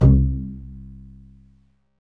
Index of /90_sSampleCDs/Roland LCDP13 String Sections/STR_Cbs FX/STR_Cbs Pizz